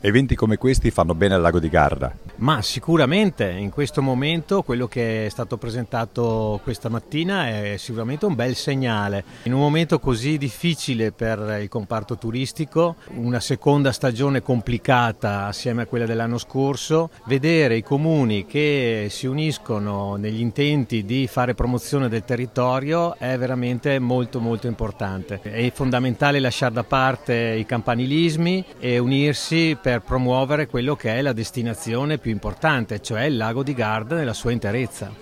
Le nostre interviste agli organizzatori della manifestazione: